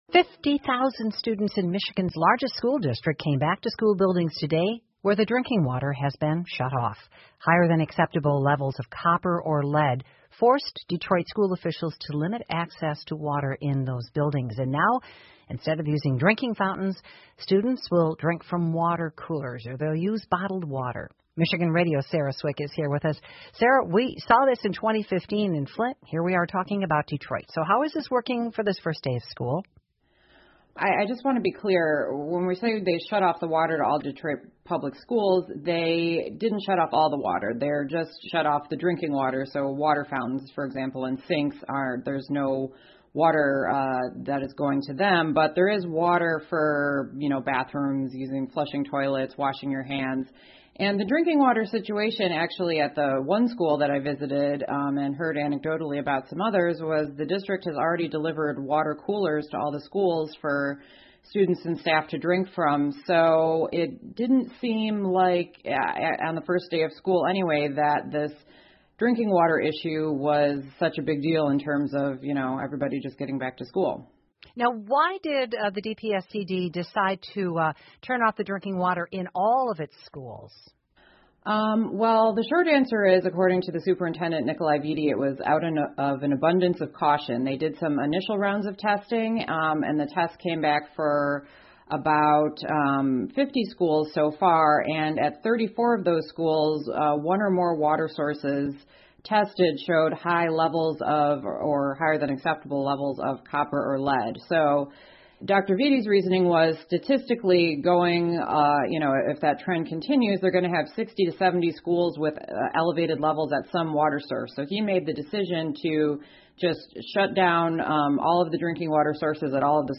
密歇根新闻广播 底特律的学生重返学校 听力文件下载—在线英语听力室